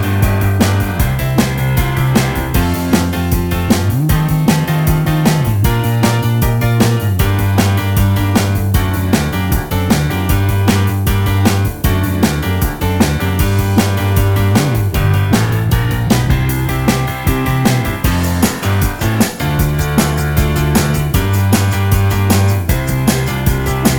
no Backing Vocals Rock 3:41 Buy £1.50